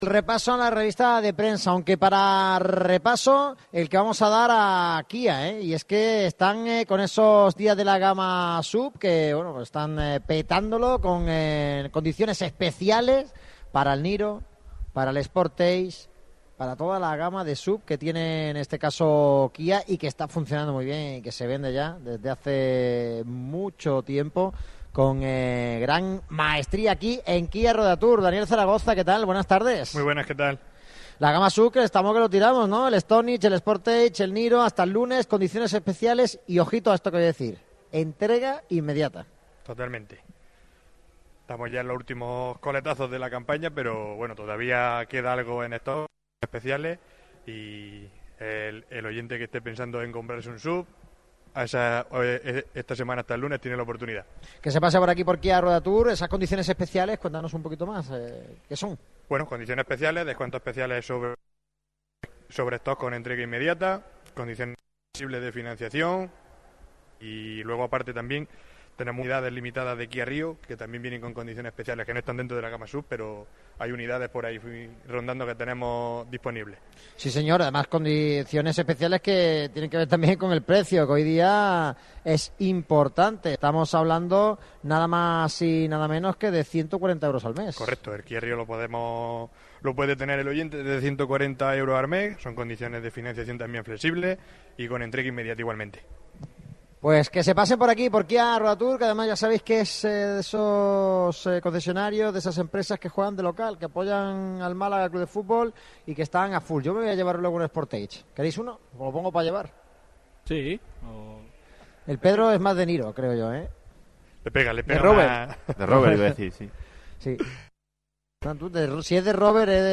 analizan toda la atualidad del deporte malagueño en el concesionario de KIA Ruedatur, ubicado en la calle París, 82, Málaga.